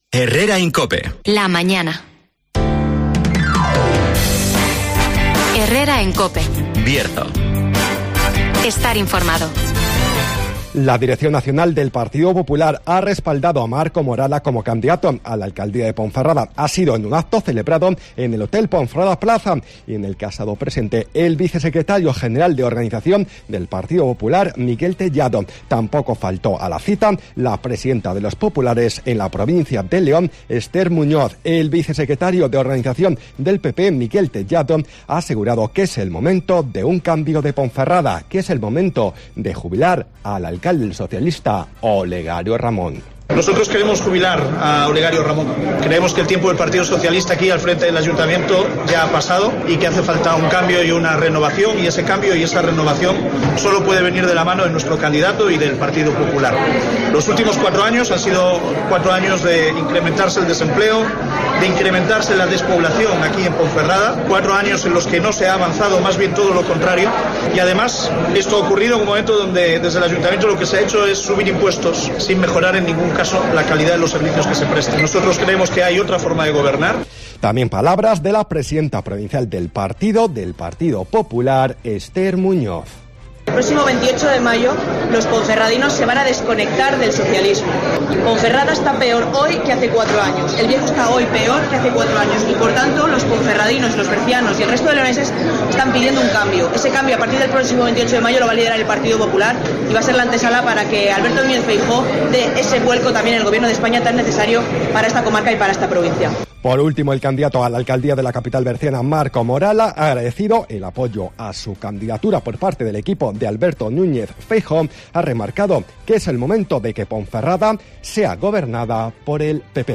-Resumen de las noticias
-Entrevista a la cantante